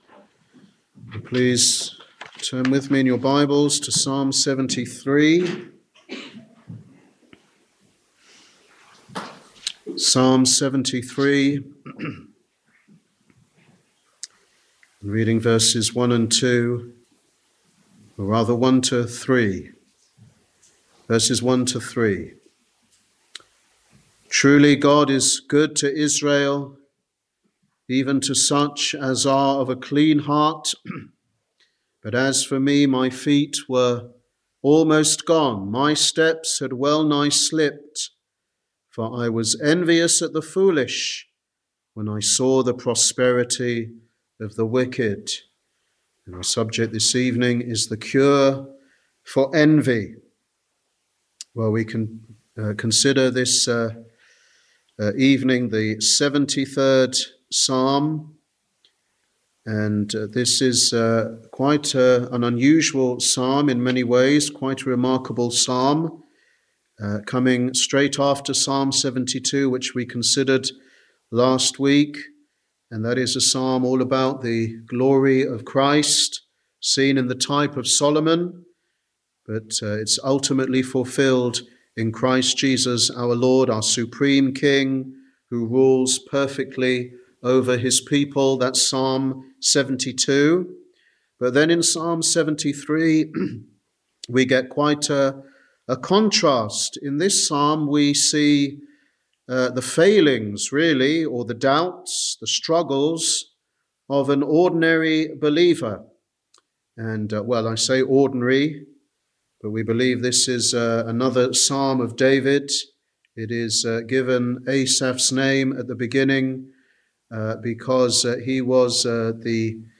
Wednesday Bible Study
Sermon